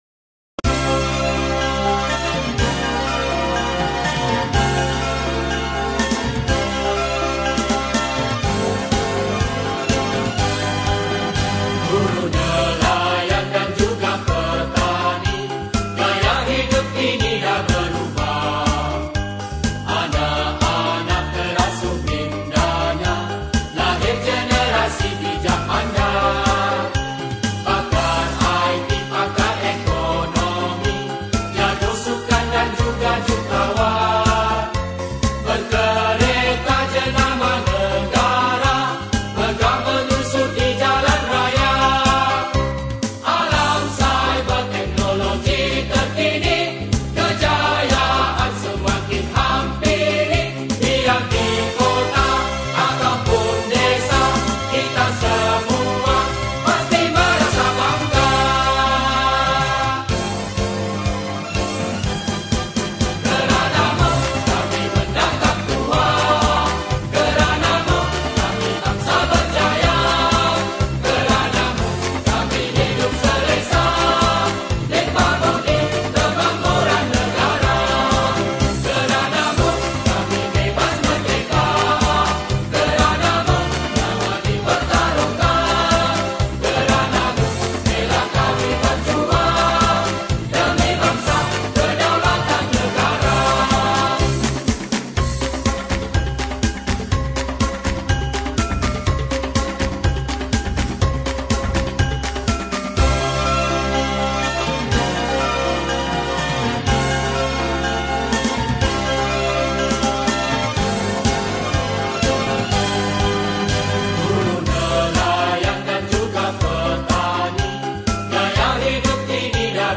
Patriotic Songs
Skor Angklung